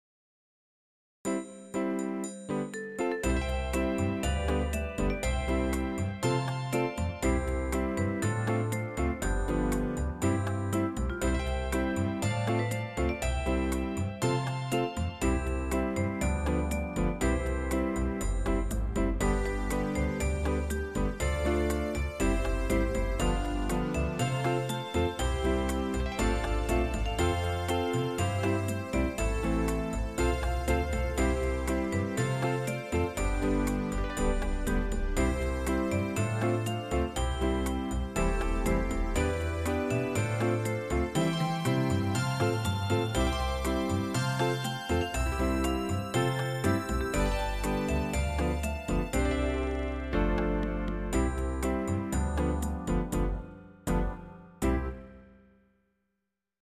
オリジナルはヘ長調(F)ですが、ハ長調(C)でアレンジしています。コード進行は怪しいです(^^;